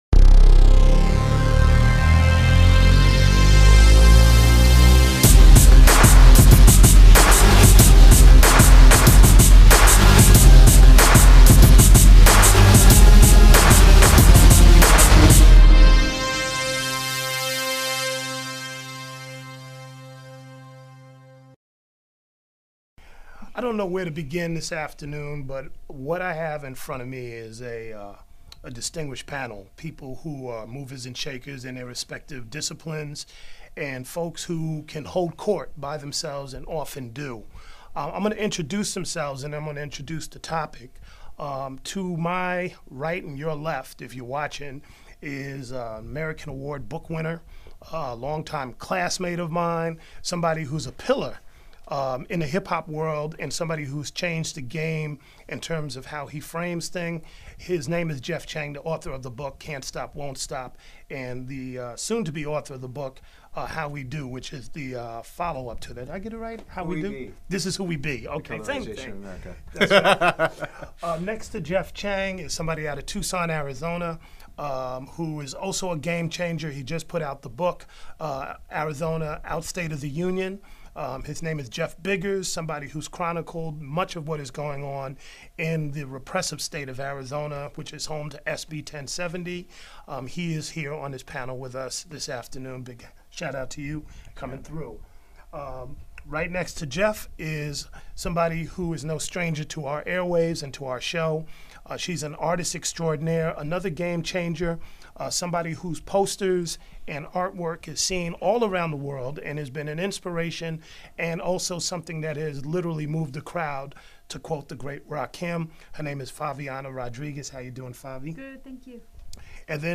A roundtable of activists and organizers discussing education.
Education Roundtable